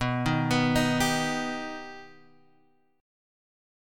B+ Chord
Listen to B+ strummed